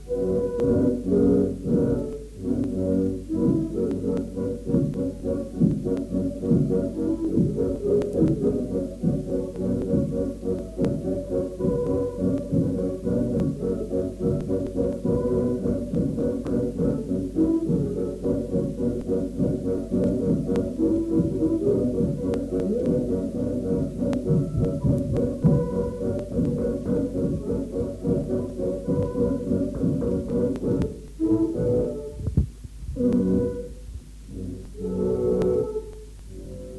Genre : morceau instrumental
Instrument de musique : accordéon diatonique
Danse : bourrée